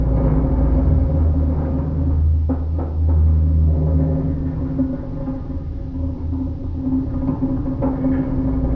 metal_low_creaking_ship_structure_loop.wav